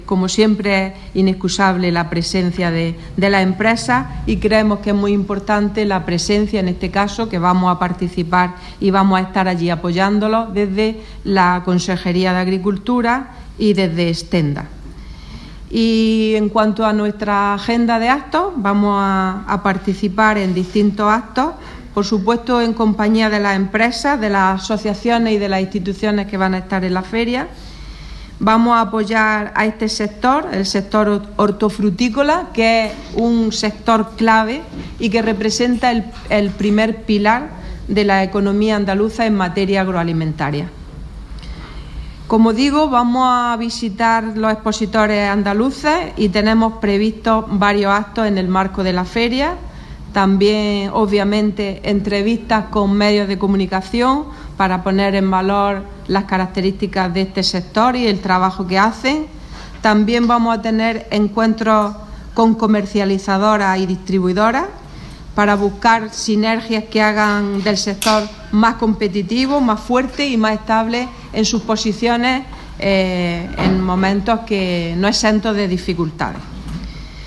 Declaraciones de Carmen Ortiz sobre presencia andaluza en Fruit Logistica 2017